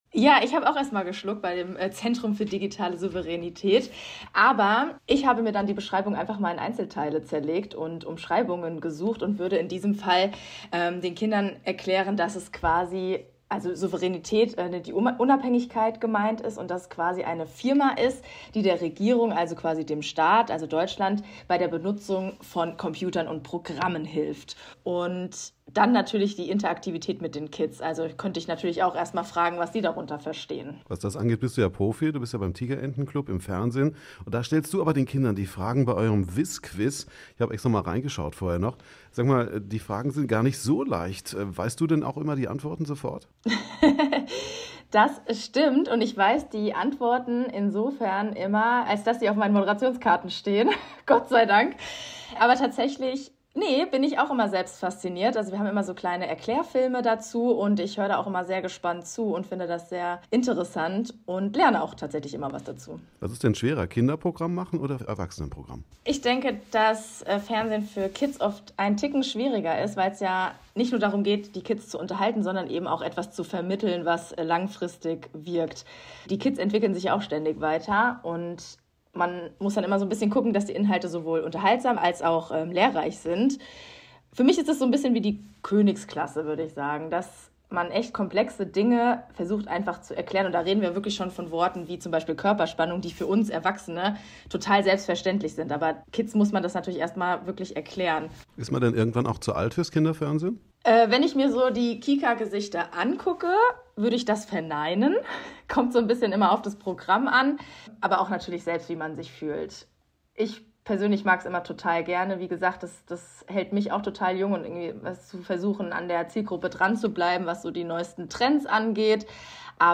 Wir haben mit ihr gesprochen.